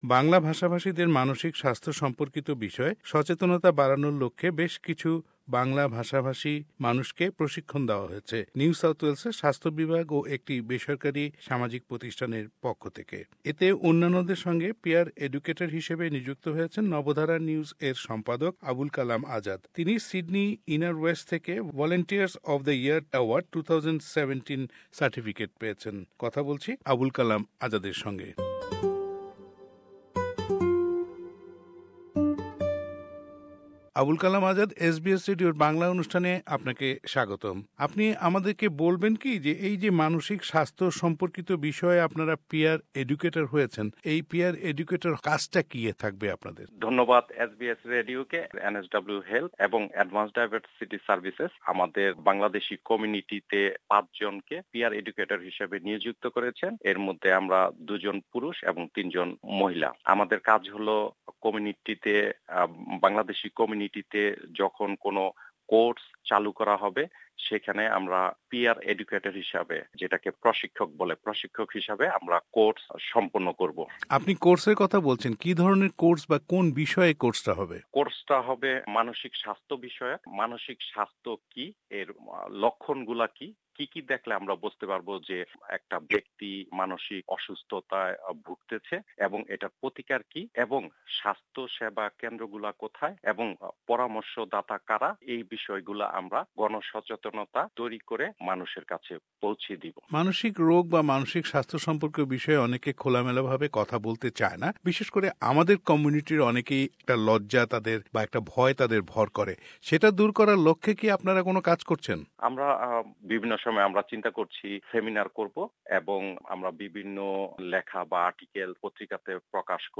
Mental Health : Interview